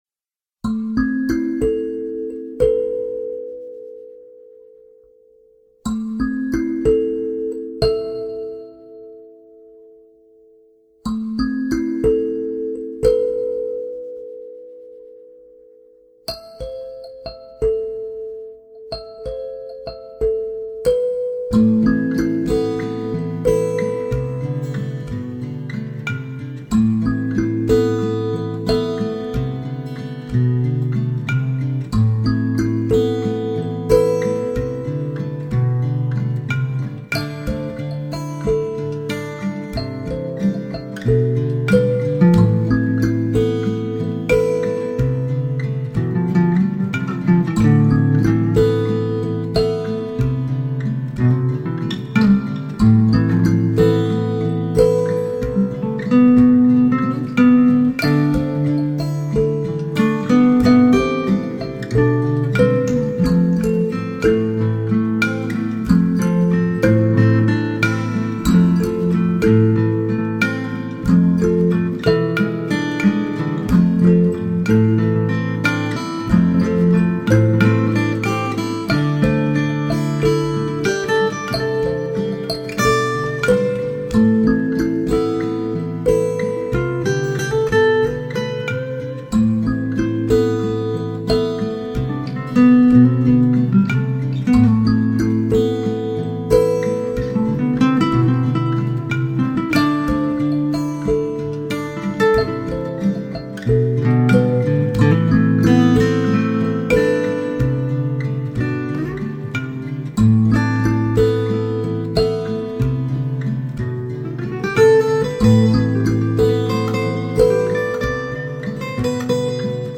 Sansula standard en La min 440Hz
La sansula est un kalimba fixé sur une caisse de résonance recouverte d’une peau tendue. Le son est absolument incroyable : intime, très doux et très réconfortant.